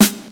• 00s Hip-Hop Snare G# Key 111.wav
Royality free acoustic snare tuned to the G# note. Loudest frequency: 3244Hz
00s-hip-hop-snare-g-sharp-key-111-nmd.wav